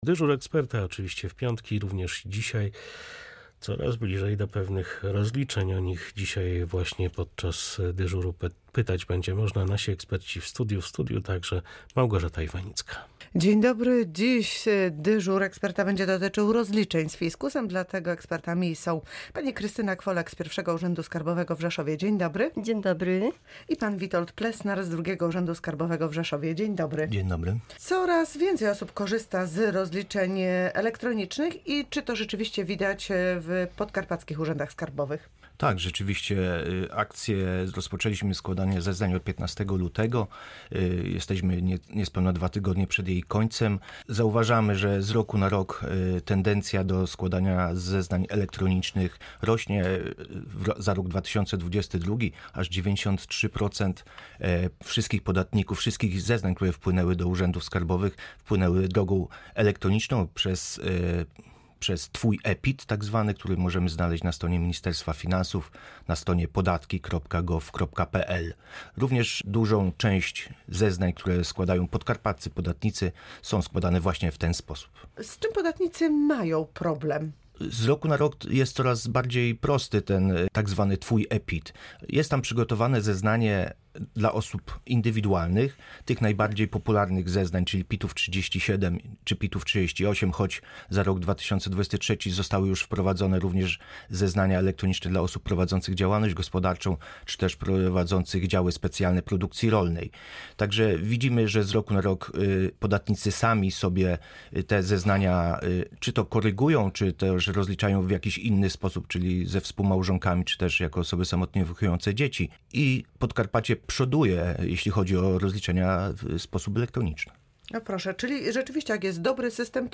Ulgi w rozliczeniu PIT-u. Dyżur ekspertów skarbówki